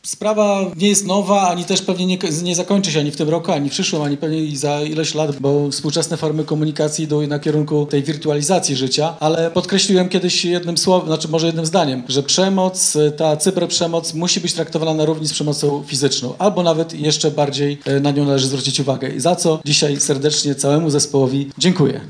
Na premierze nie zabrakło burmistrza miasta Wojciecha Iwaszkiewicza, który jest inicjatorem akcji ”Giżycko mówi Nie dla HEJTU”. Włodarz po raz kolejny podkreślił, że cyberprzemoc powinna być traktowana na równi z przemocą fizyczną.
burmistrz.mp3